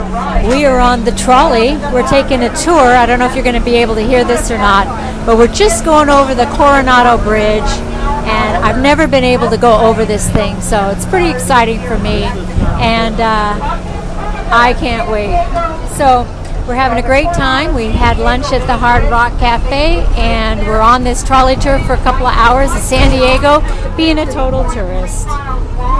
Coronado Bridge on Trolley
61439-coronado-bridge-on-trolley.mp3